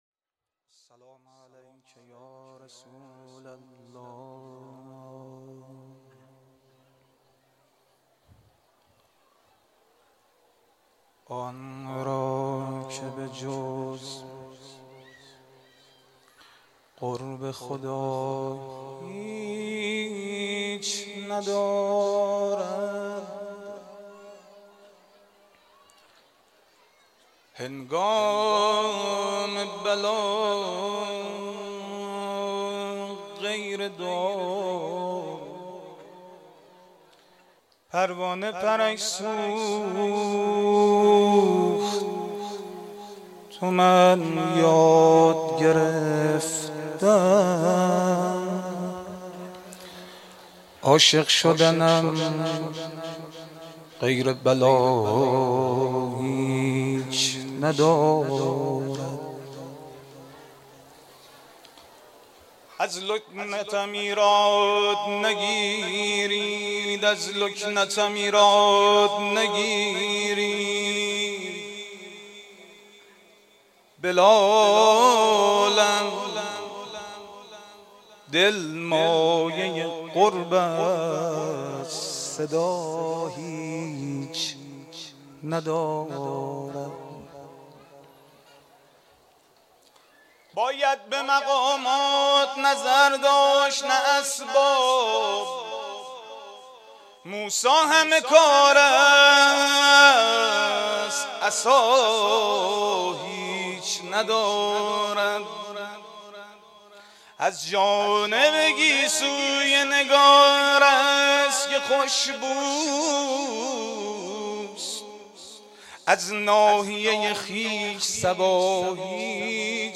مولودی میلاد ولادت پیامبر مدیحه